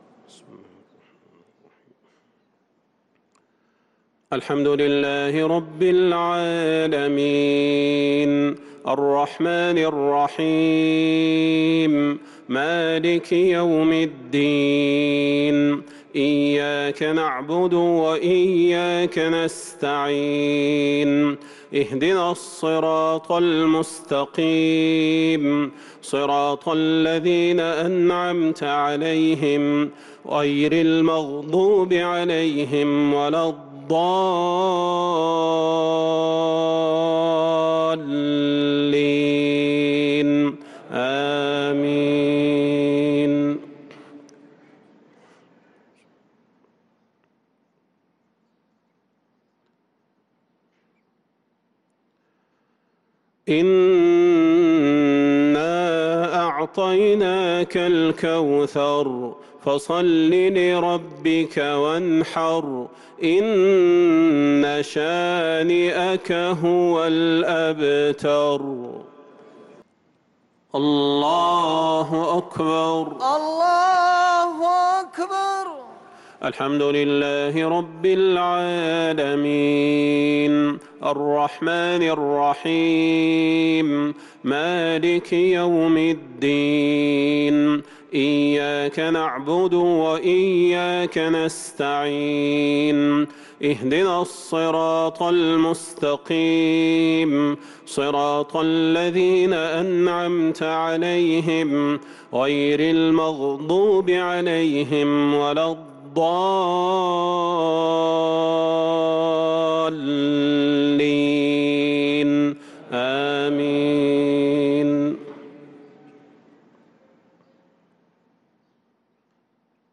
صلاة العشاء للقارئ صلاح البدير 2 رمضان 1443 هـ